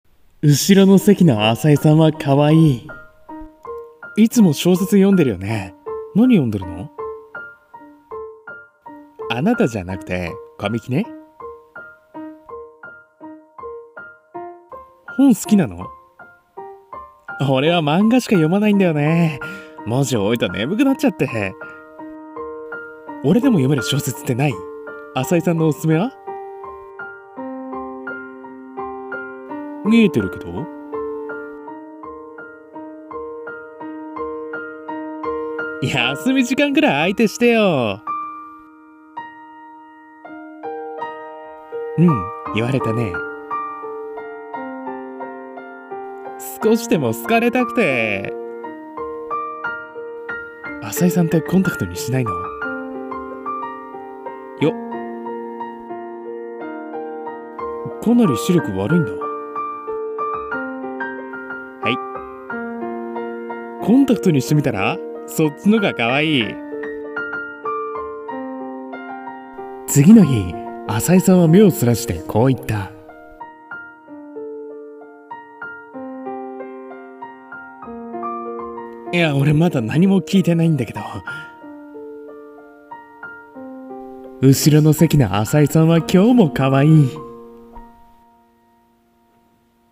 【二人声劇】浅井さんは今日も可愛い